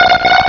pokeemerald / sound / direct_sound_samples / cries / natu.aif